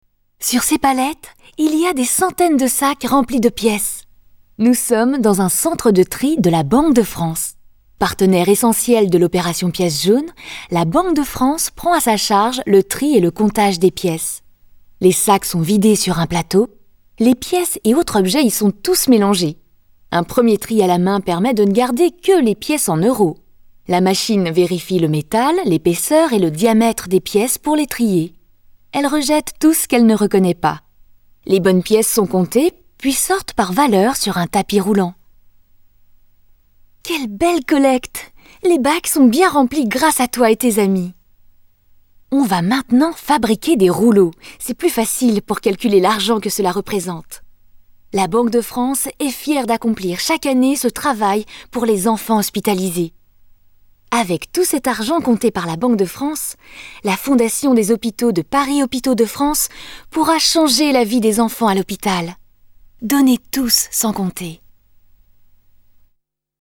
Voix Off Institutionnel Centre de tri Banque de France